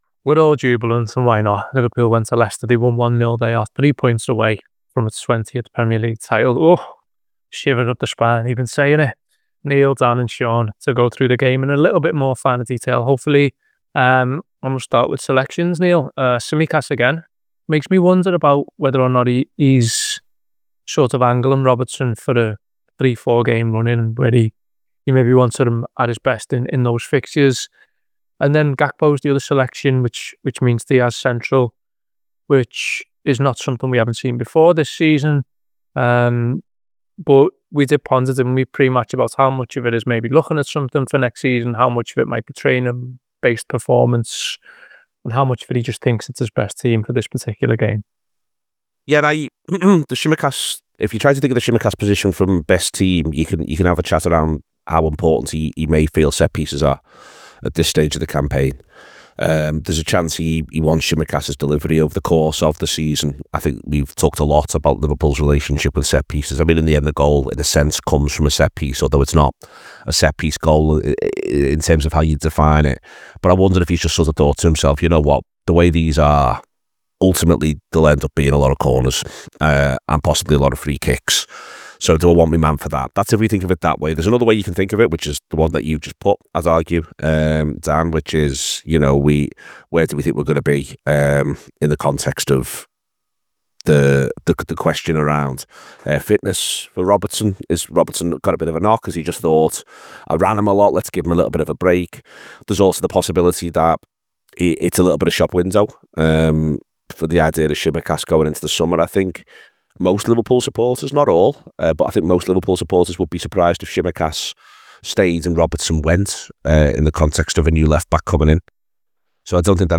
Below is a clip from the show – subscribe to The Anfield Wrap for more review chat around Leicester City 0 Liverpool 1…